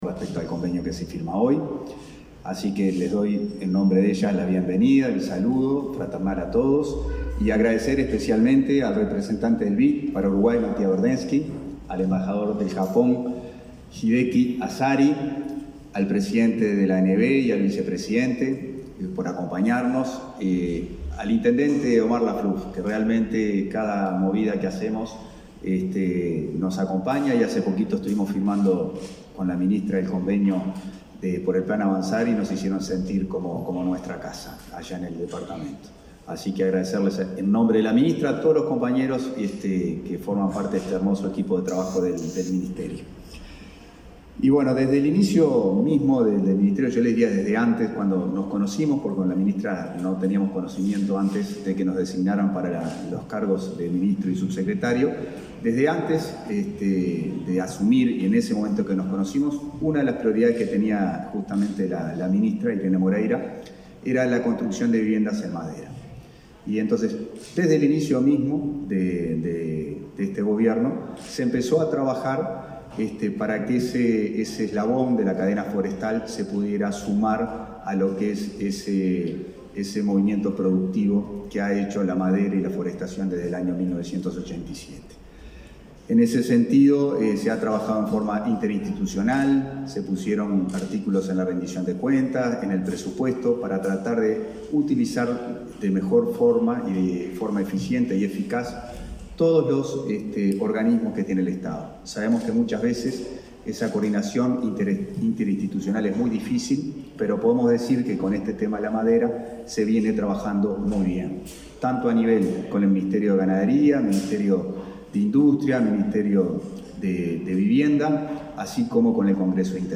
Conferencia de prensa por firma de convenio entre el MVOT y el BID